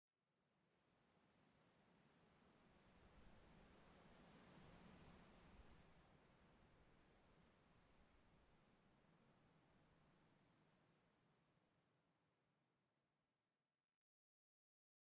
sounds / block / sand / wind12.ogg
wind12.ogg